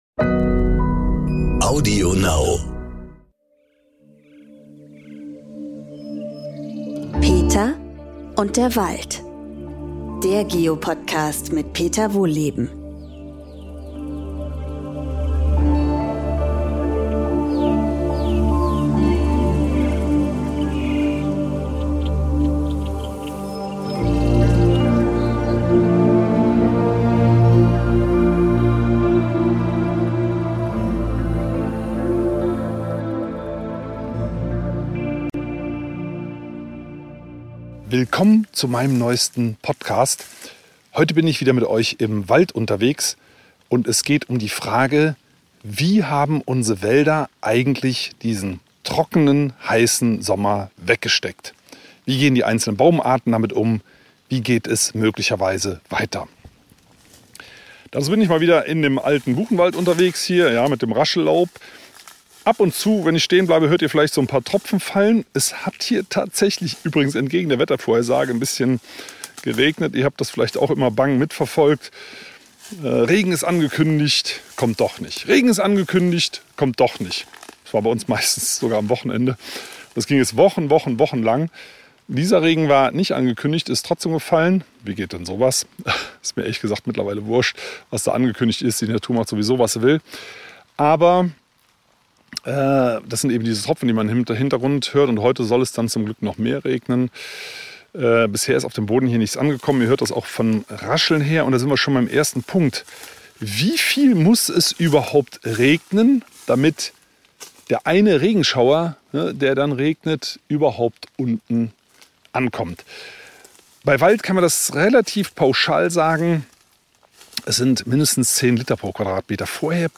Wir sind wieder mit Peter Wohlleben im Wald unterwegs. Diesmal erklärt er uns, wie die Bäume eigentlich durch den heißen und sehr trockenen Sommer kommen sind. Welche Baumarten haben gelitten?